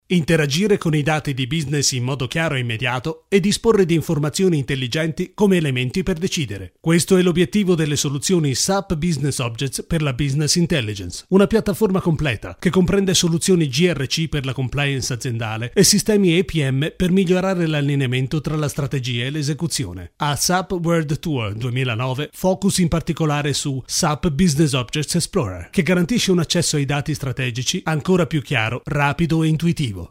Sprechprobe: Industrie (Muttersprache):
I try to be an "any purpose" voice.